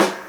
Snare 4.wav